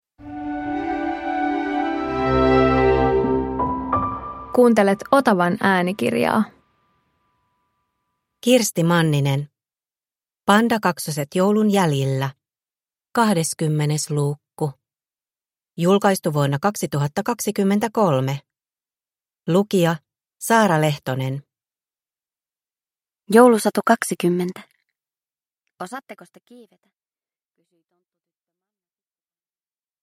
Pandakaksoset joulun jäljillä 20 – Ljudbok